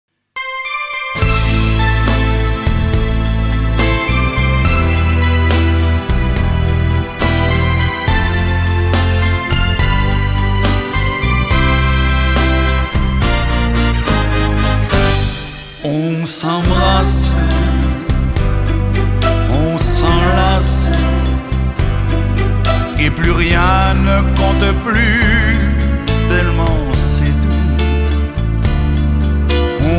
Genre : Slow Rock.